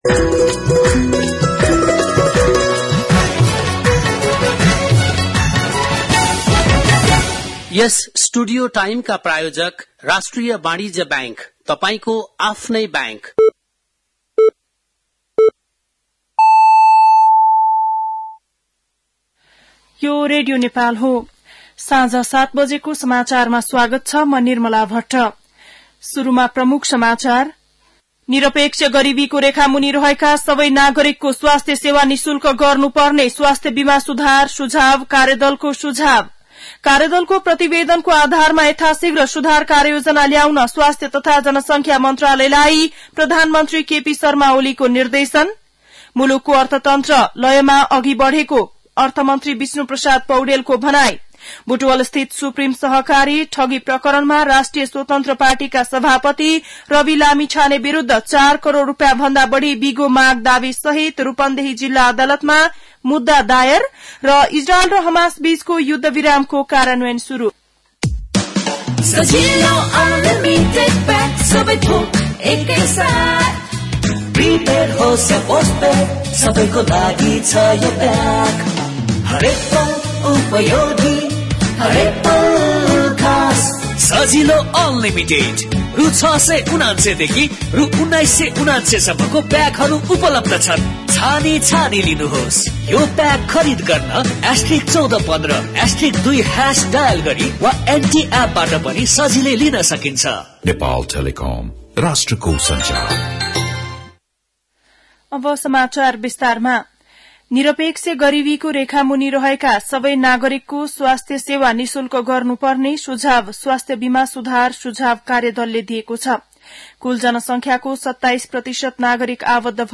बेलुकी ७ बजेको नेपाली समाचार : ७ माघ , २०८१
7-PM-Nepali-News-10-6.mp3